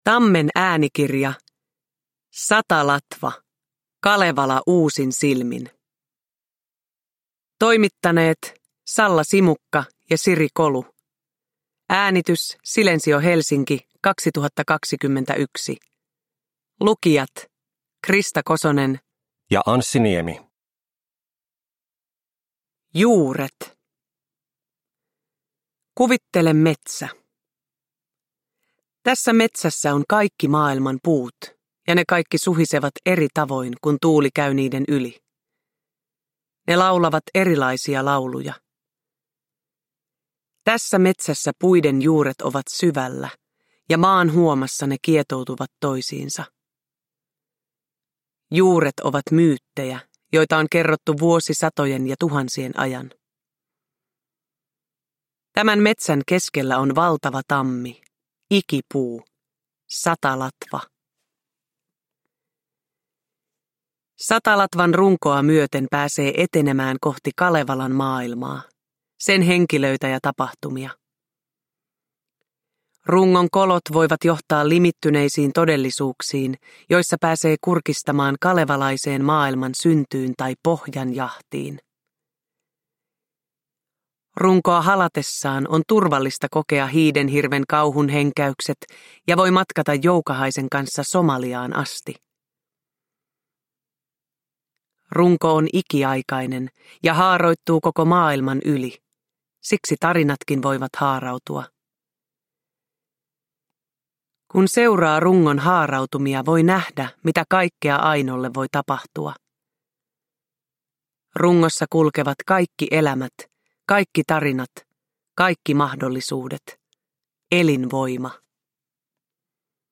Satalatva. Kalevala uusin silmin – Ljudbok – Laddas ner